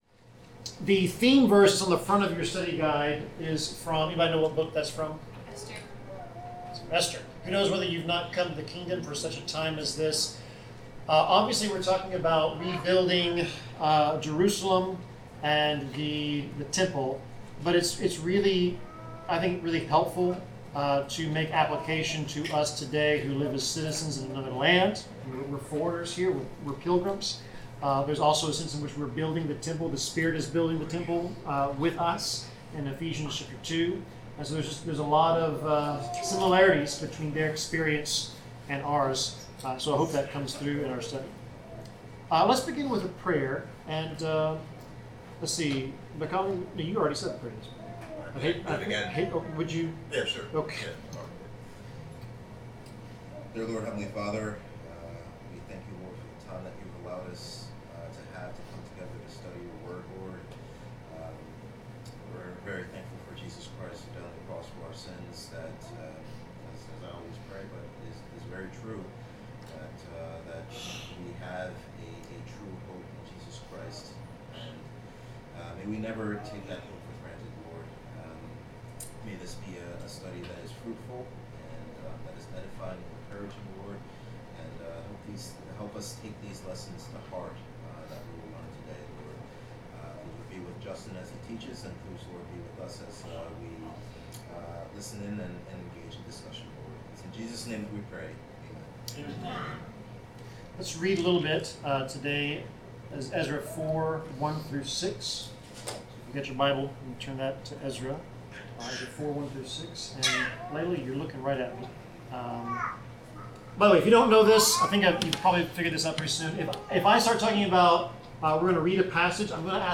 Bible class: Ezra 4-6
Service Type: Bible Class